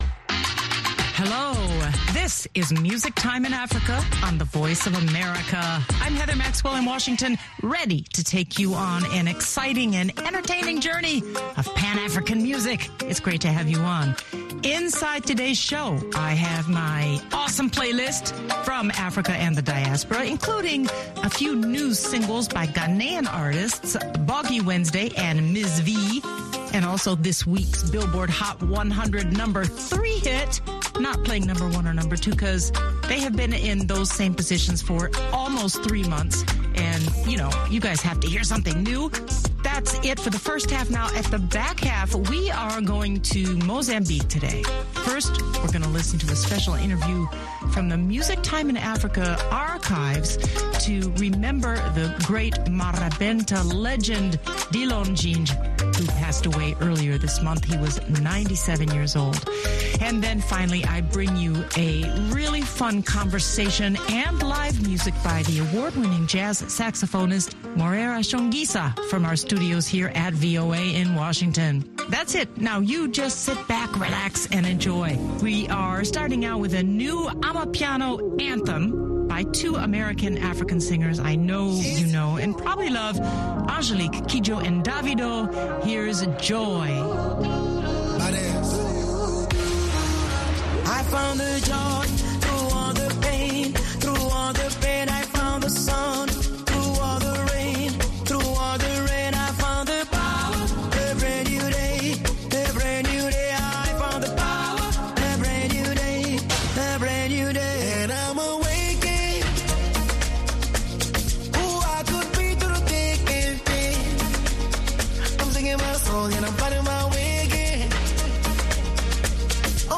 She also welcomes award-winning jazz saxophonist, Moreira Chonguiça to the TV studio at VOA in Washington for live music and conversation.